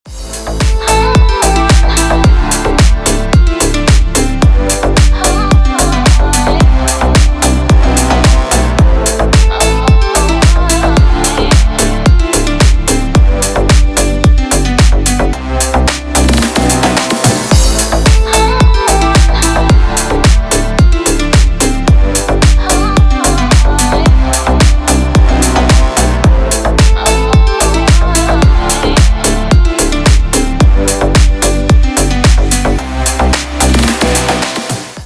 Отрывки dance треков 2022